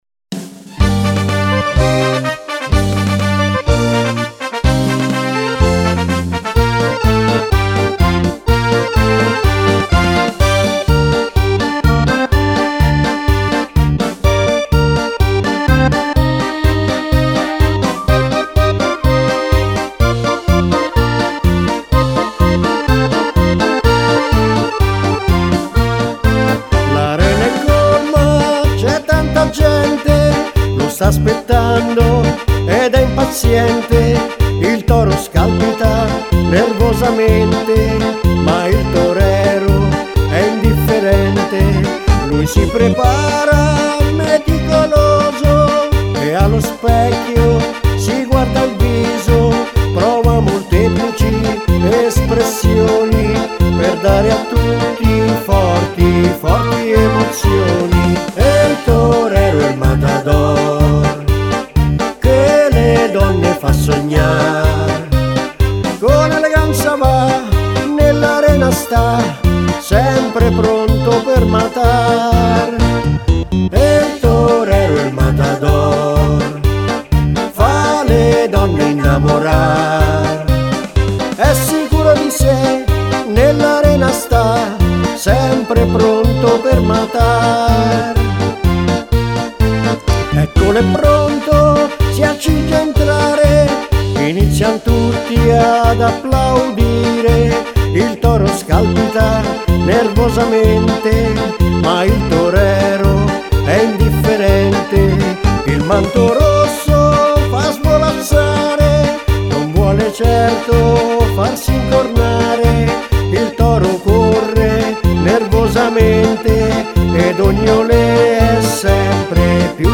Paso-Doble
Paso-doble canzone